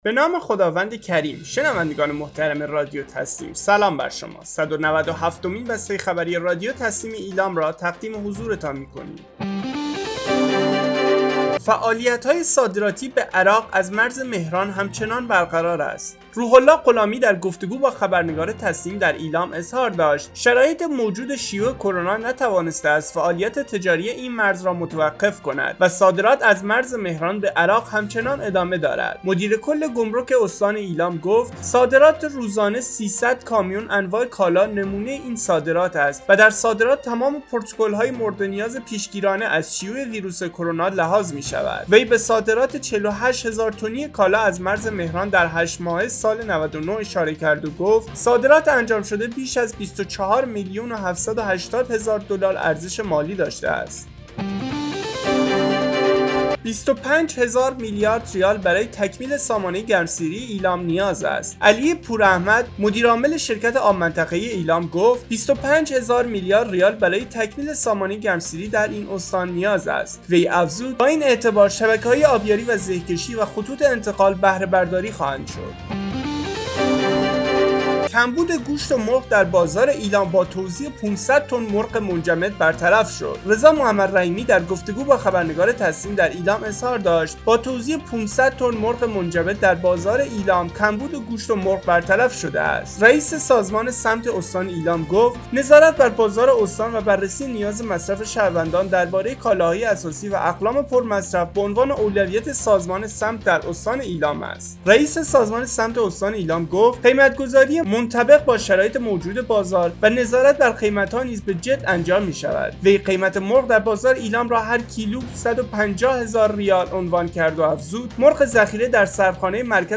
گروه استان‌ها- آخرین و مهمترین اخبار استان ایلام در قالب بسته خبری